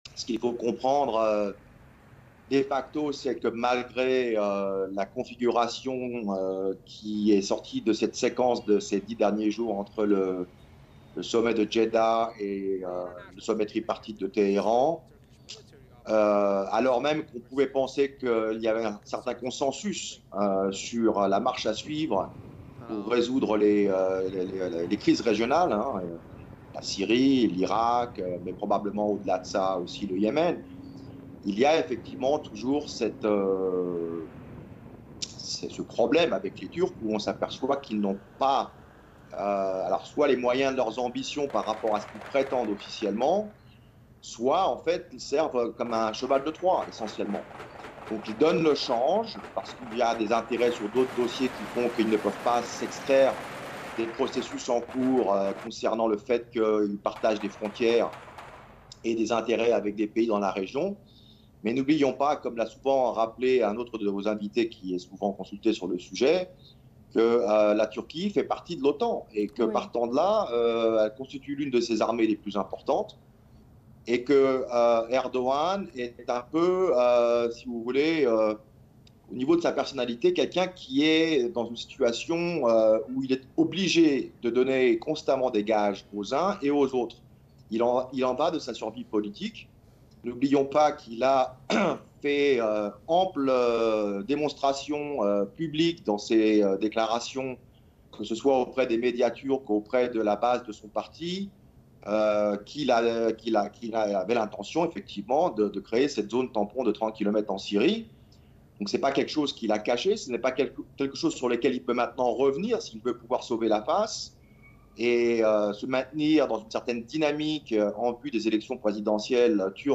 Mots clés Iran Turquie interview Eléments connexes Trump : pourquoi veut-il faire croire ses mensonges sur l’Iran au monde ?